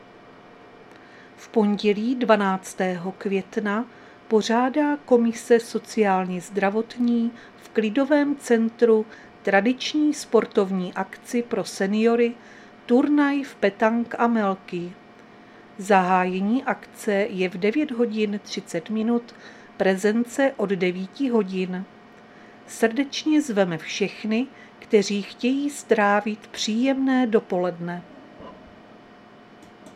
Záznam hlášení místního rozhlasu 5.5.2025